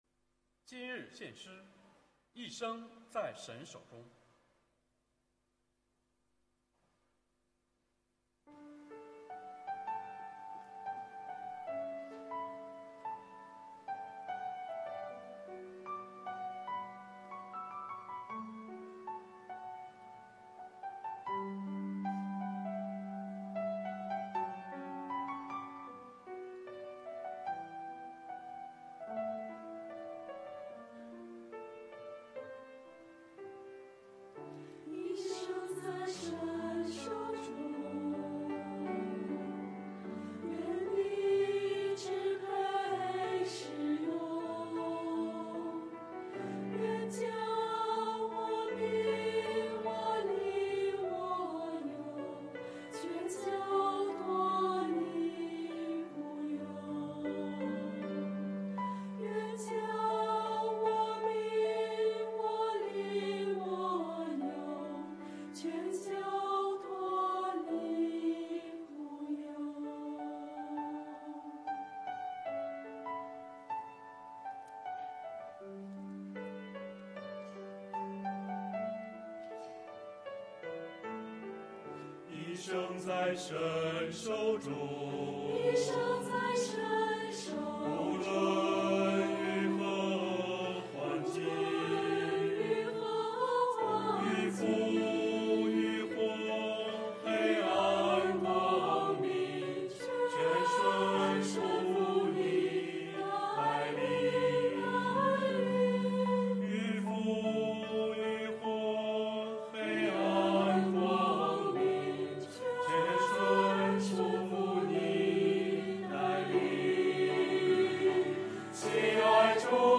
团契名称: 青年、迦密诗班
诗班献诗